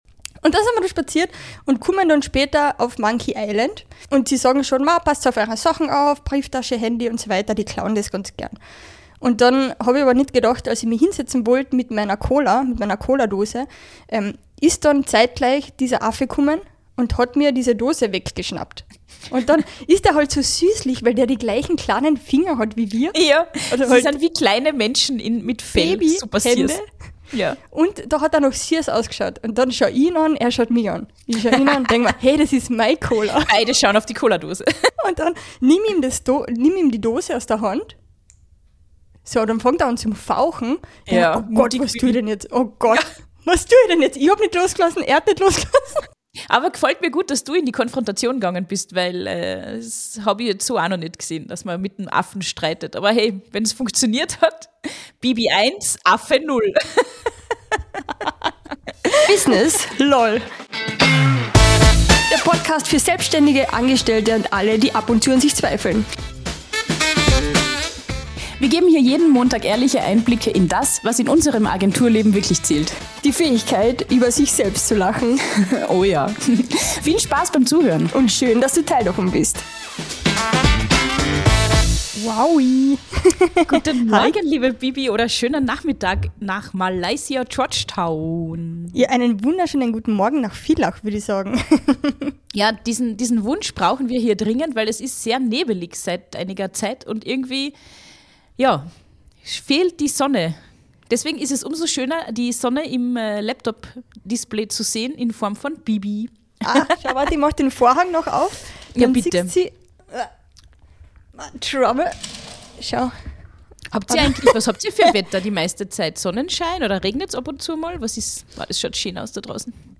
Beschreibung vor 4 Monaten Die Leitung nach George Town, Malaysia, hat wieder geglüht